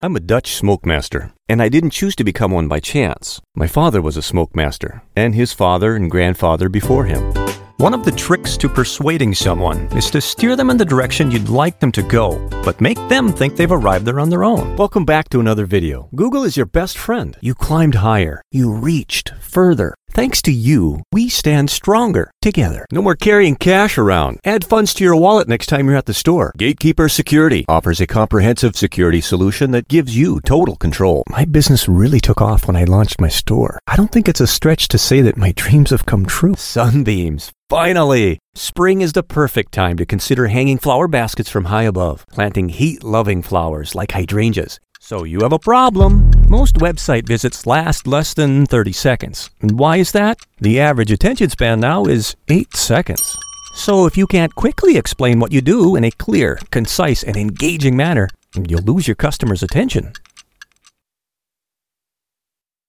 Professionally trained. Pro gear and studio.
Conversational - Personable - Guy Next Door style of voice. Believable and Friendly.
middle west
Sprechprobe: Industrie (Muttersprache):